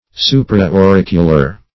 Search Result for " supra-auricular" : The Collaborative International Dictionary of English v.0.48: Supra-auricular \Su`pra-au*ric"u*lar\, a. (Zool.)